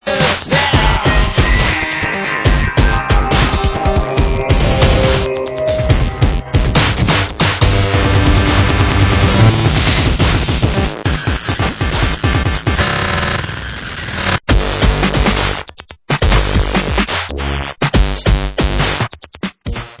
Alternativní hudba